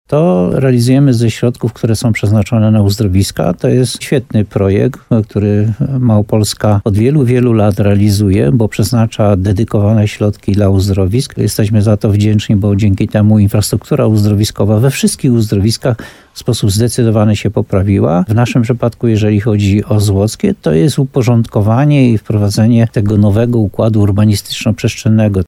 Jak mówi burmistrz Jan Golba, chodzi przede wszystkim o wykonanie nowego deptaku, odrębnej ścieżki rowerowej czy parków kieszonkowych, w który będzie można usiąść i podziwiać tutejsze krajobrazy.